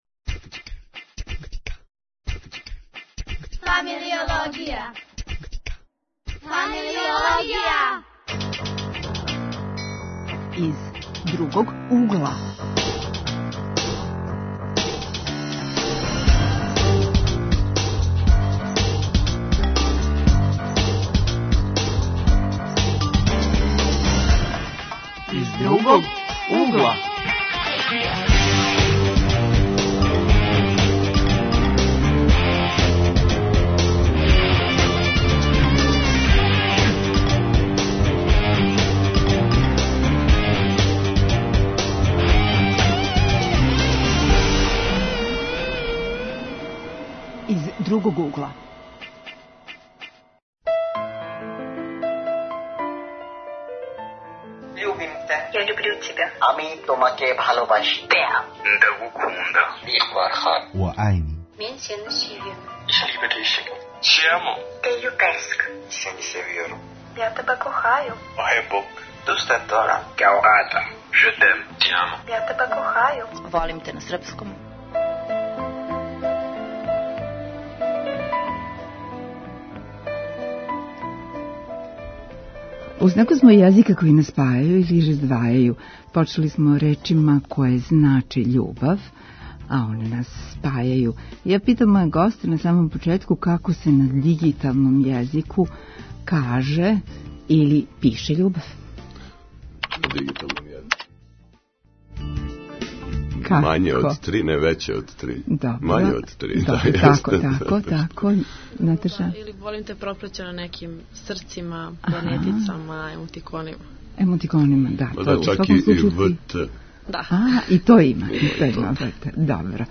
Гости у студију су студенти